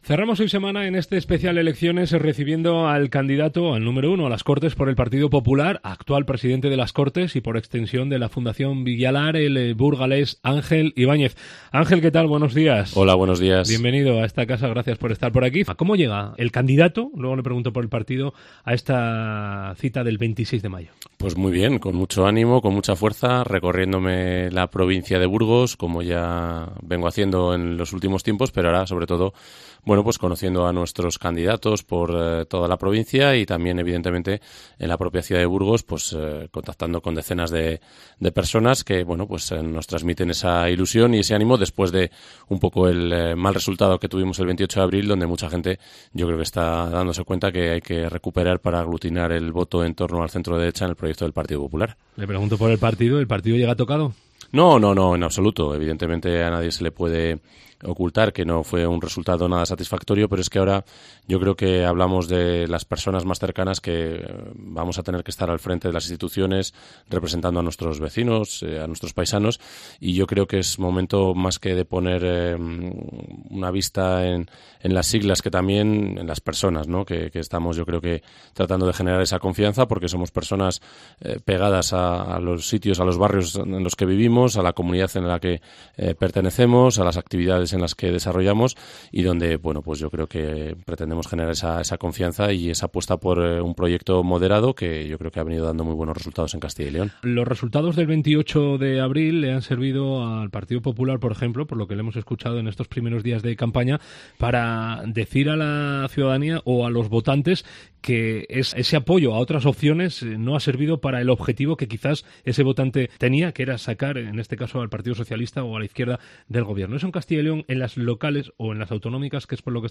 El candidato del PP a las Cortes de Castilla y León, Ángel Ibáñez, responde a las preguntas